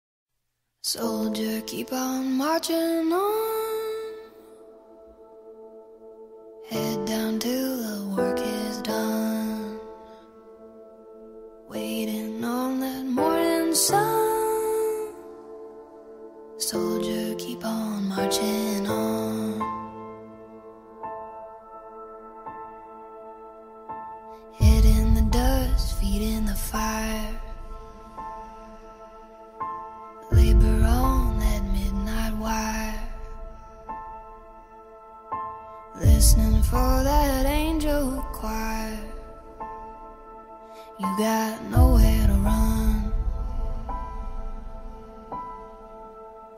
• Качество: 128, Stereo
спокойные
пианино
красивый женский вокал
alternative